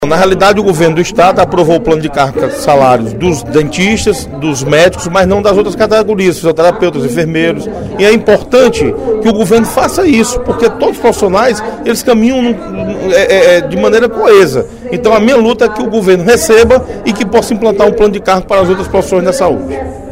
O deputado Osmar Baquit (PSD) chamou atenção, durante o primeiro expediente da sessão plenária desta quarta-feira (10/07), para a situação dos profissionais de saúde que lutam para conseguir o Plano de Cargos e Carreiras (PCC), a exemplo dos médicos e dentistas cearenses.